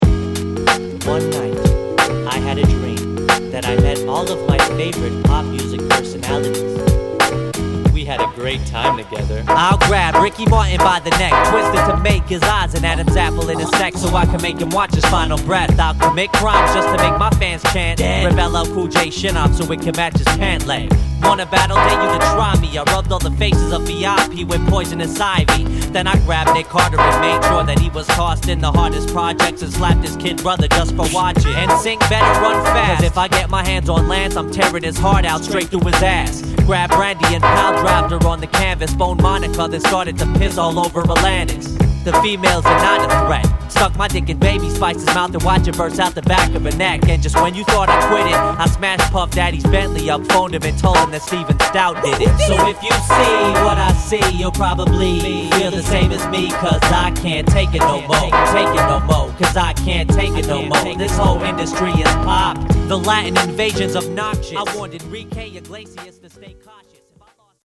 少しチリノイズが出る為ディスカウントしています。